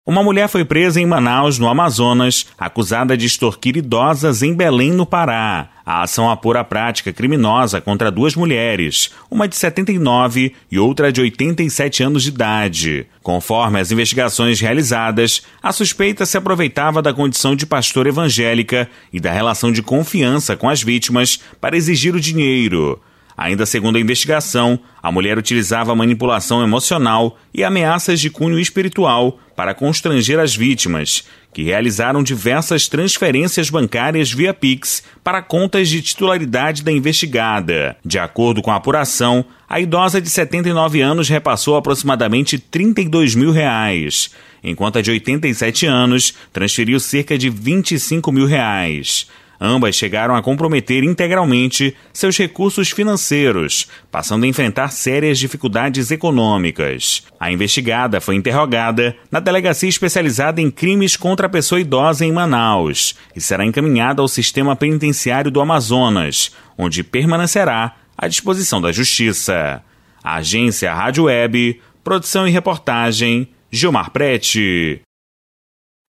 Mais uma frente de atendimento à população foi confirmada durante entrevista do deputado Tio Pablo no Sorriso Show, da Rádio Avalanche FM 102.7.